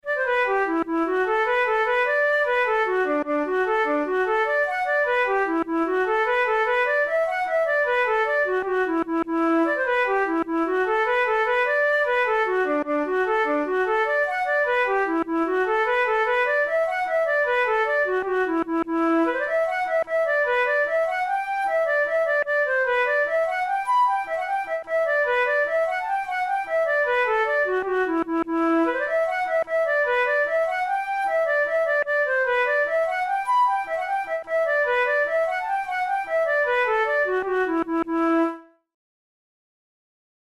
InstrumentationFlute solo
KeyE minor
Time signature6/8
Tempo100 BPM
Jigs, Traditional/Folk
Traditional Irish jig